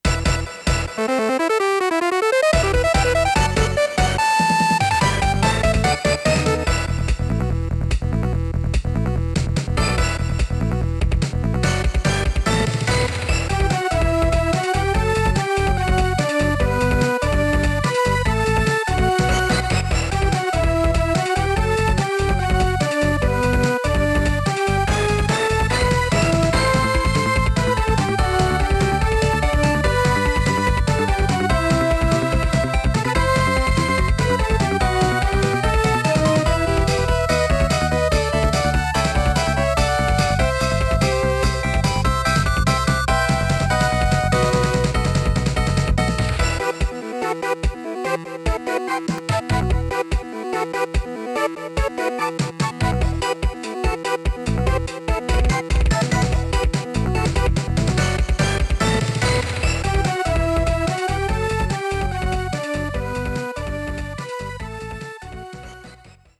All-new Super Famicom tunes reinserted back into the game.